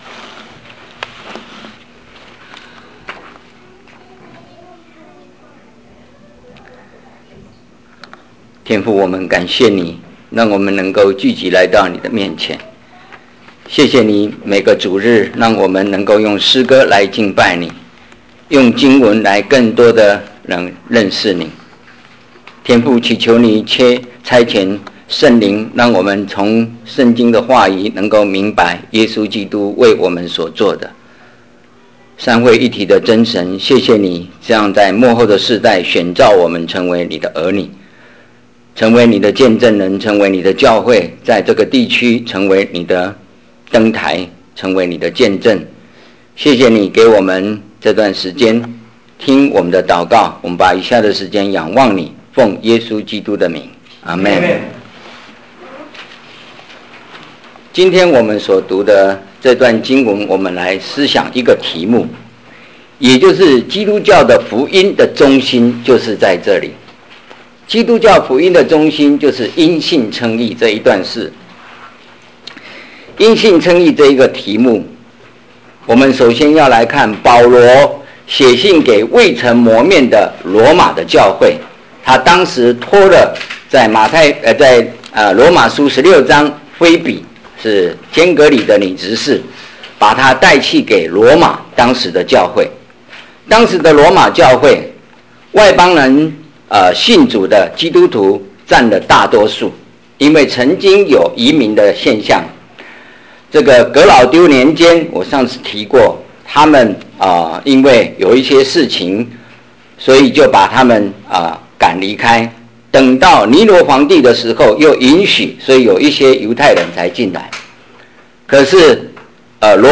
所有布道录音现都已转换成MP3格式，这样能用本网站内置播放器插件播放。